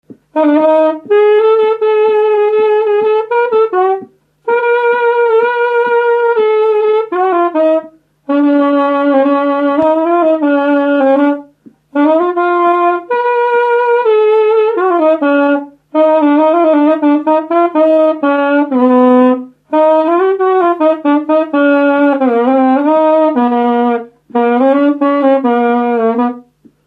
Dallampélda: Hangszeres felvétel
tárogató Műfaj: Lassú csárdás Gyűjtő